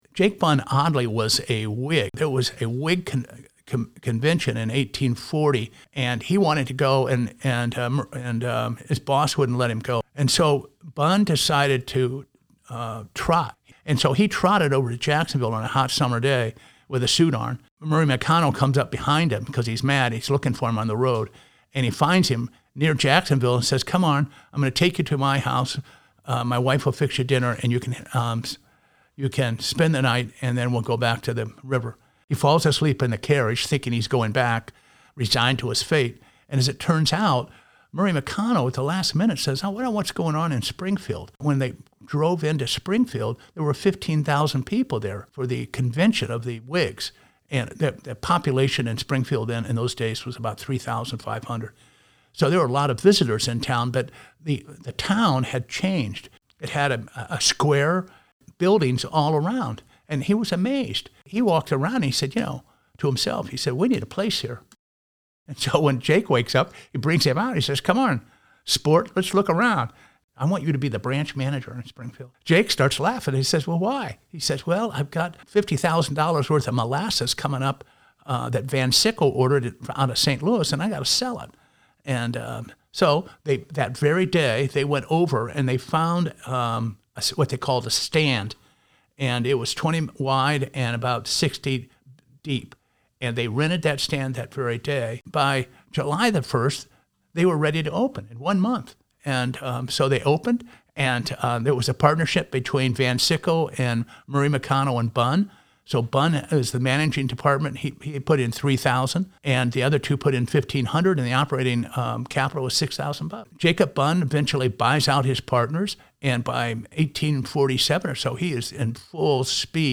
Segment of an Oral History interview